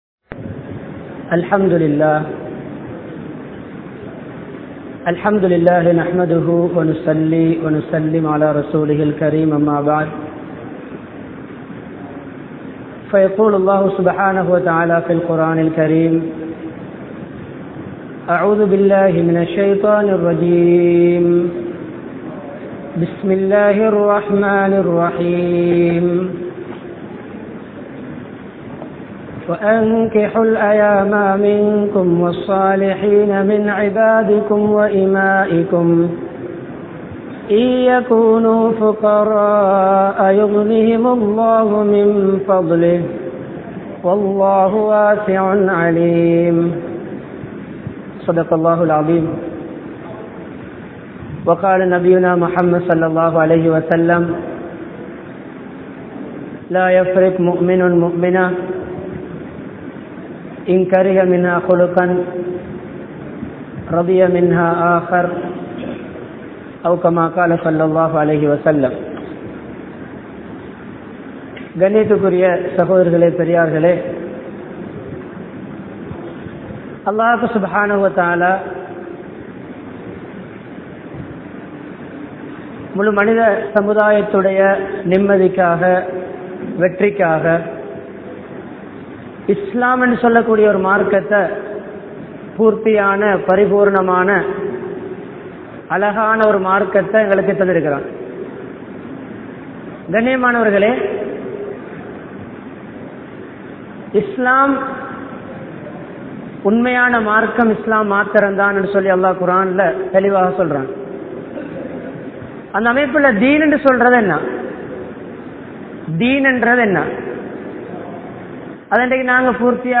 Thirumanaththin Noakkam (திருமணத்தின் நோக்கம்) | Audio Bayans | All Ceylon Muslim Youth Community | Addalaichenai
Wellampitiya, Gothotuwa, Jabbar Jumua Masjidh